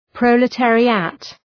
Προφορά
{,prəʋlı’teərıət}
proletariat.mp3